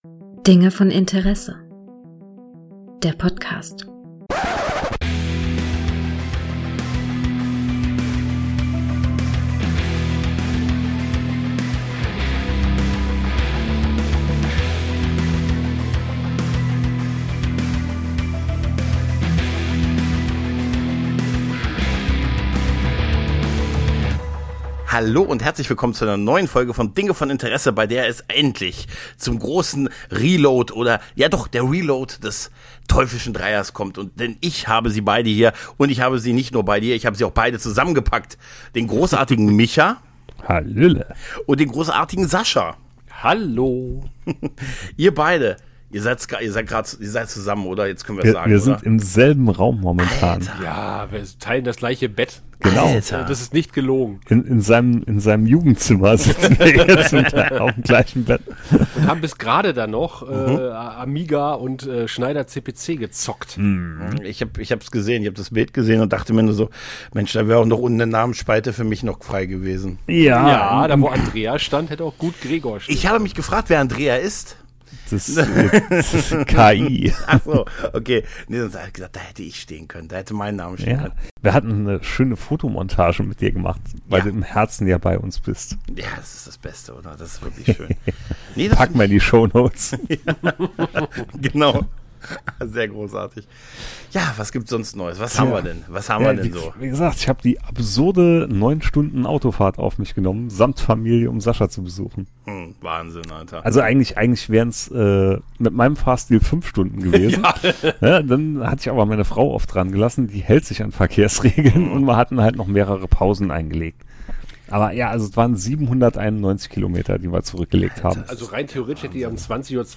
Wir kommen dabei schnell vom Hölzchen aufs Stöckchen. Garantiert nicht Strukturiert. :)